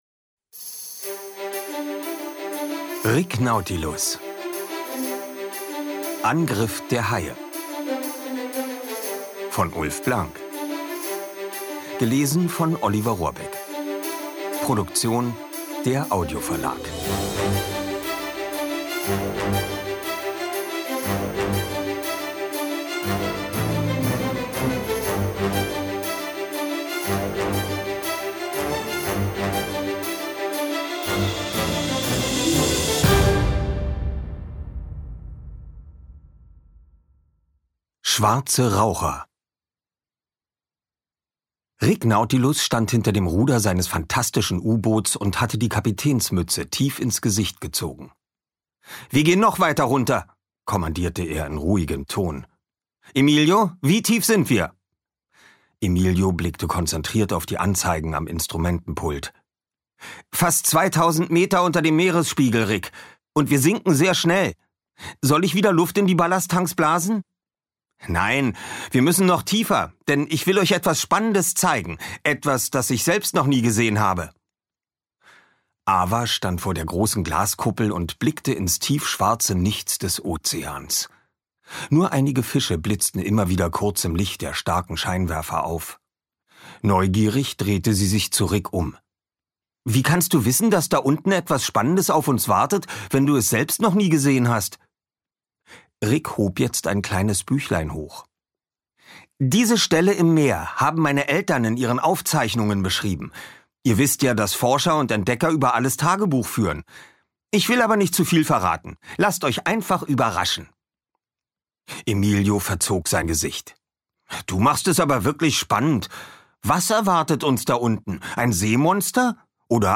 Rick Nautilus – Teil 7: Angriff der Haie Ungekürzte Lesung mit Musik mit Oliver Rohrbeck
Oliver Rohrbeck (Sprecher)